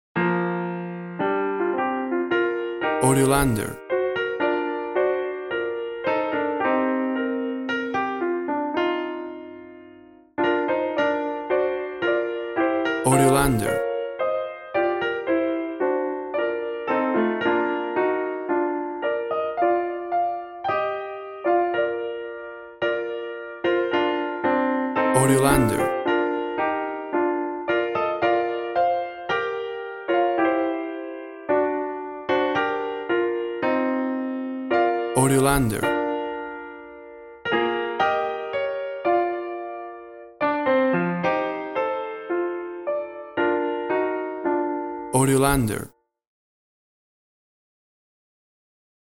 Grand Piano
Tempo (BPM) 130